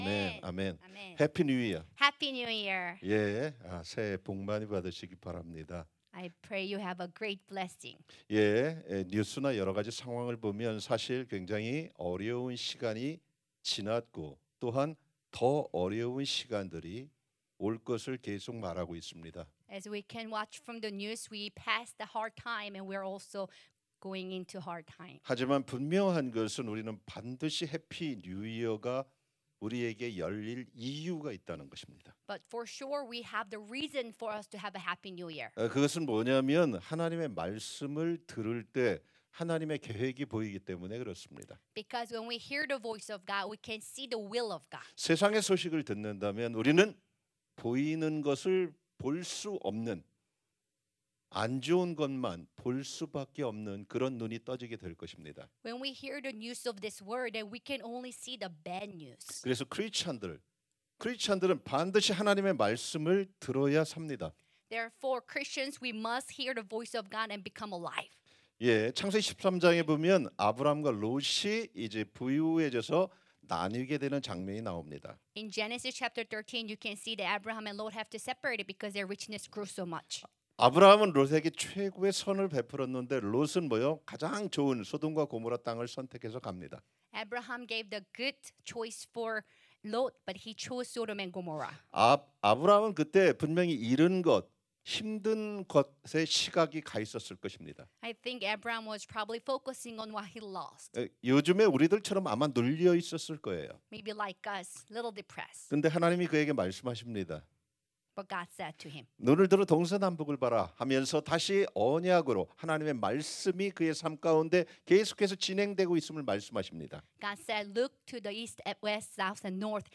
2020-2021 송구영신예배 [사 60장]
관련공지보기▶ 오디오 설교